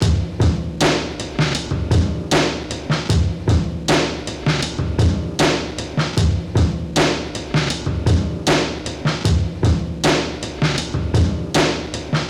morepixels78bpm.wav